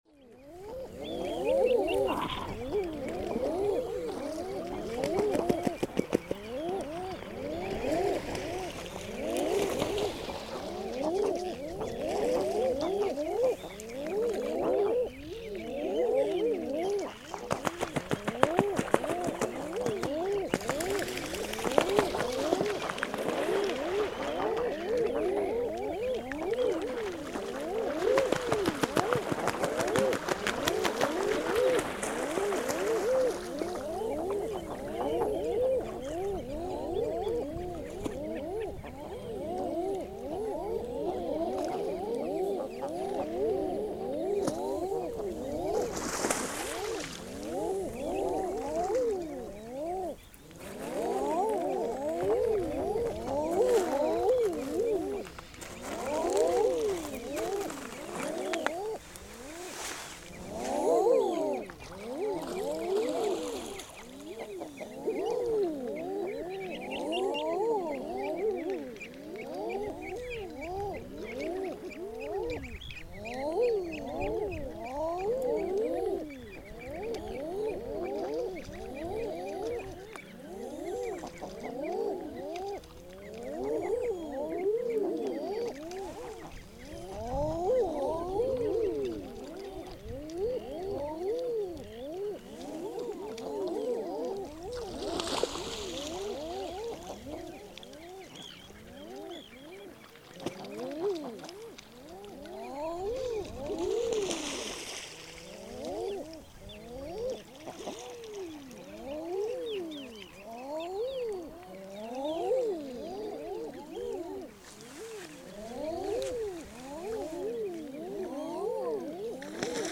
Æðarfugl á Seltjarnarnesi
Milli kl. 5 og 6 hinn 12. júní, fór ég út á Nes og hljóðritaði hljóð æðarfugls í fjöruborðinu. Allhvöss norðanátt var á Nesinu, svo að í upphafi gekk erfiðlega að finna hljóðnemunum stað, til að vindurinn heyrðist ekki á upptökunum. Besti staðurinn reyndist vera suðurströndin í skjóli við grjótgarð og melgresi. Í flæðarmálinu voru kollur með nokkra unga og blikar, sem voru að atast sín á milli og í kollunum.
Upptakan hefst með því, að ég set upp hljóðnemanna þar sem hópur æðarfugla er í fæðuleit með buslugangi. Þegar líður á upptökuna, færðust flestir fuglarnir austar með ströndinni (til vinstri) og skriðu þar á land. Upptakan verður því hljóðbærari eftir því sem á líður. Notast var við tvo Sennheiser ME66 hljóðnema, sem klæddir voru Rycote Softy vindhlífum og stillt upp í ca. 100°.
Upptakan, sem hér heyrist, er líklega besti samfelldi kafli allrar upptökunnar þar eð vindur hafði ekki truflandi áhrif. Þegar líður á upptökuna fjarar undan hljóðnemunum.